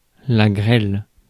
Ääntäminen
Synonyymit maigret Ääntäminen France (Paris): IPA: [la ɡʁɛl] Tuntematon aksentti: IPA: /ɡʁɛl/ Haettu sana löytyi näillä lähdekielillä: ranska Käännös Substantiivit 1. rahe Suku: f .